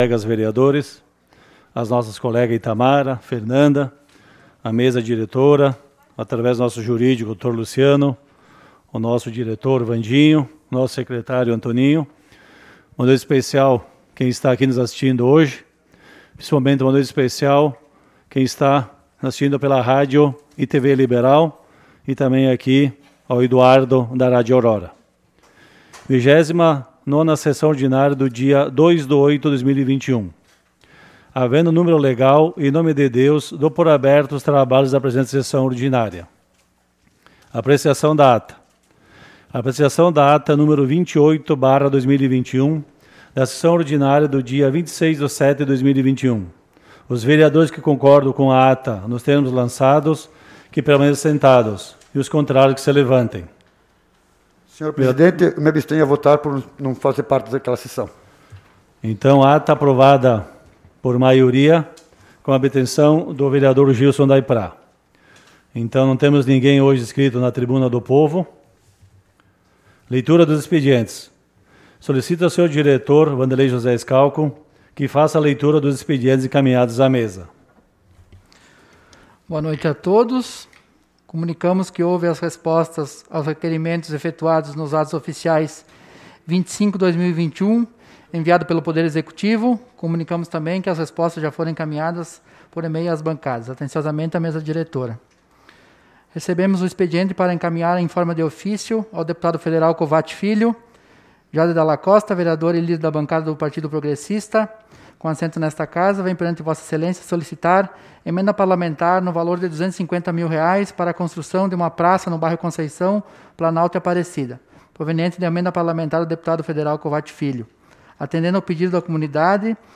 Sessão Ordinária do dia 02 de Agosto de 2021 - Sessão 29